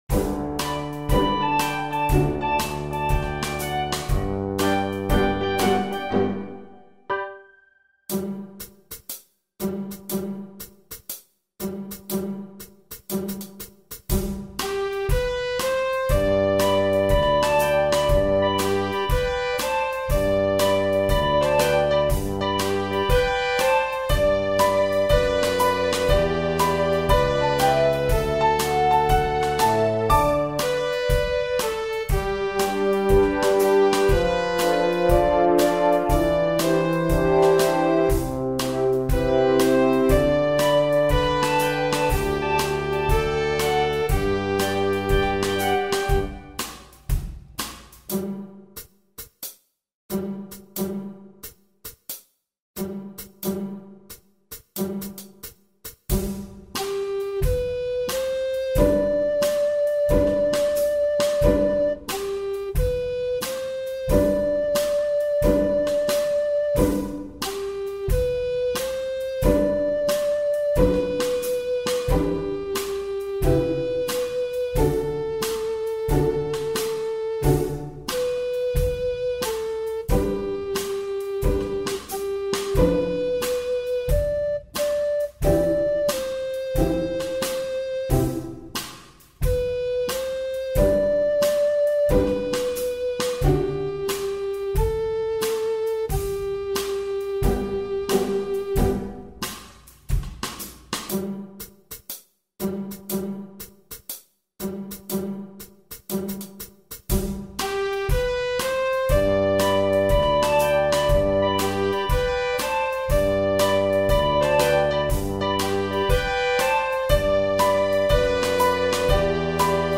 Recorder in 2 parts